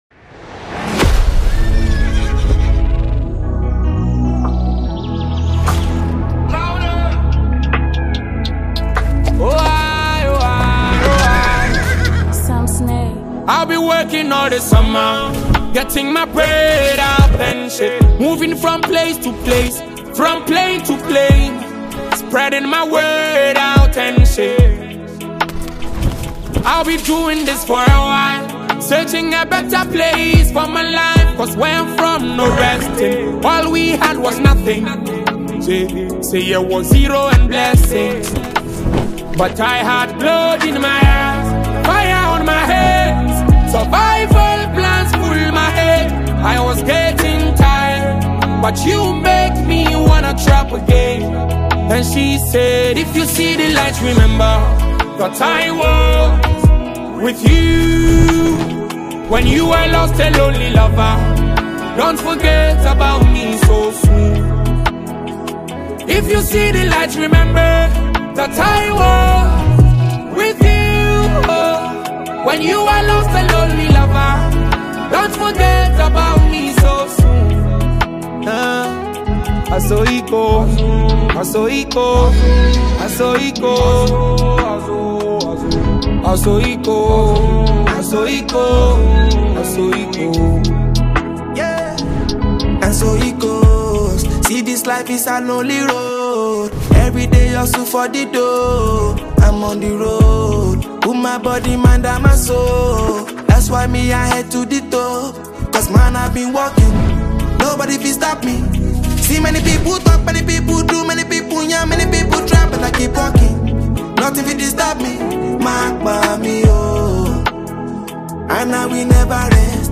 Ghanaian singer
vocals